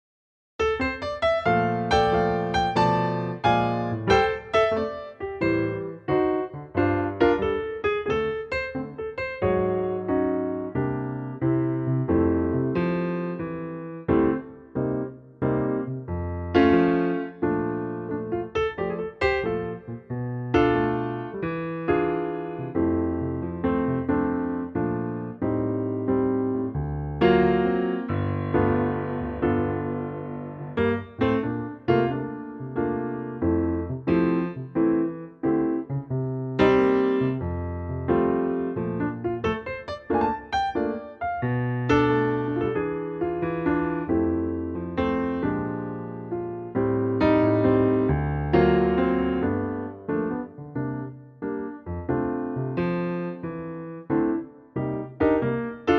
key - F - vocal range - A to A
Wonderful piano arrangement of this classic old standard.